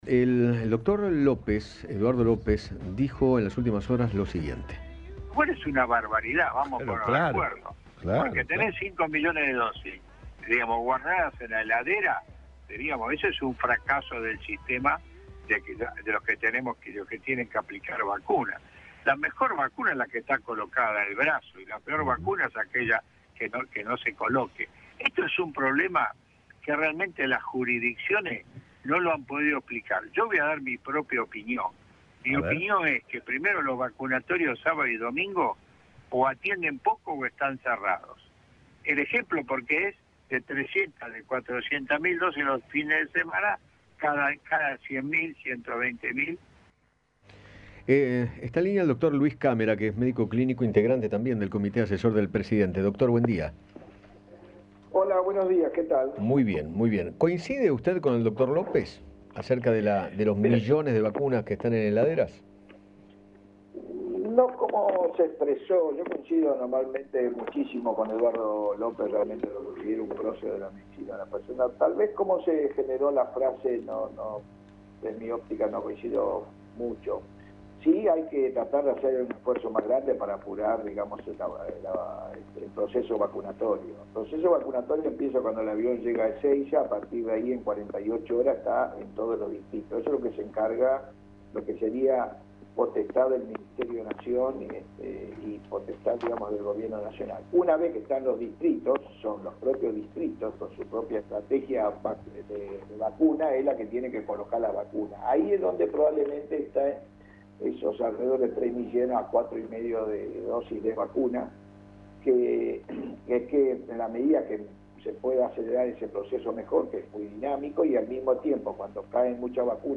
Luis Cámera, médico clínico y asesor presidencial, conversó con Eduardo Feinmann acerca de los dichos de Eduardo López, quien se refirió a la cantidad de vacunas sin aplicar y declaró que  “es un fracaso del sistema, un problema que las jurisdicciones no han podido explicar”.